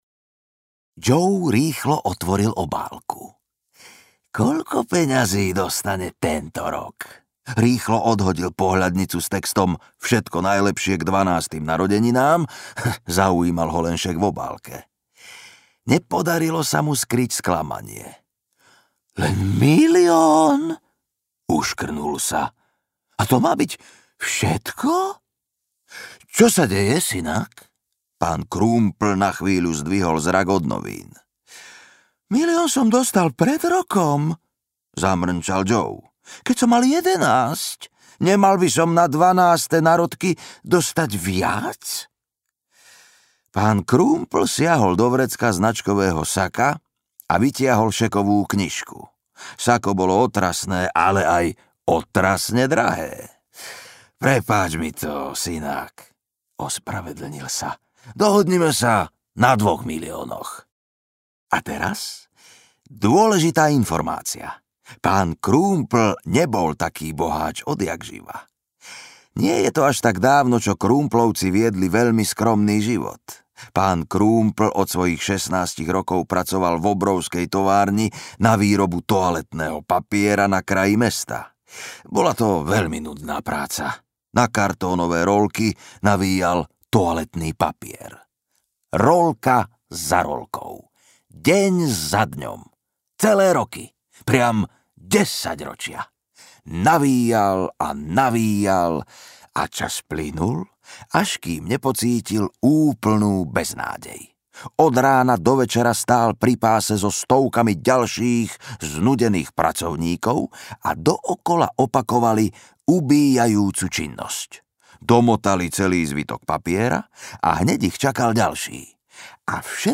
Malý milionár audiokniha
Ukázka z knihy
maly-milionar-audiokniha